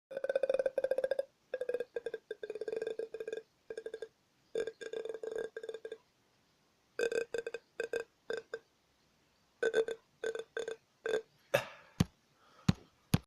Звуки измерения радиоактивного фона - щелчки дозиметра
• Категория: Счетчик радиации, счетчик Гейгера
• Качество: Высокое